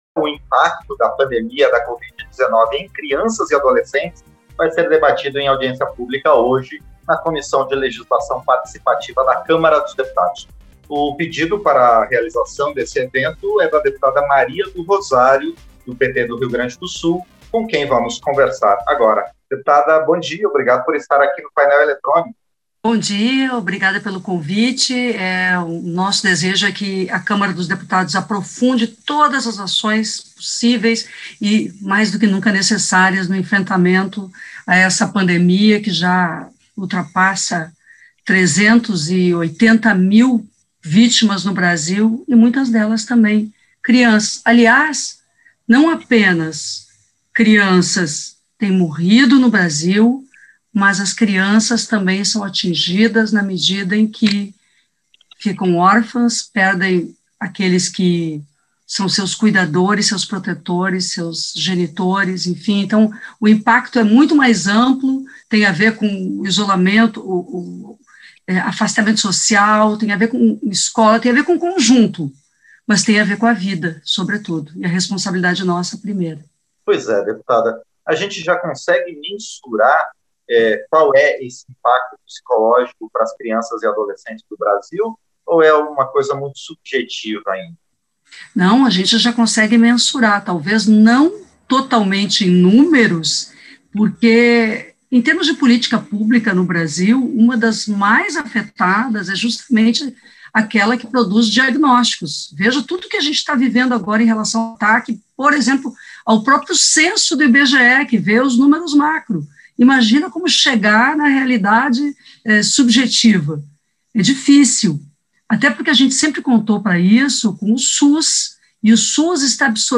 Entrevista - Dep. Maria do Rosário (PT-RS)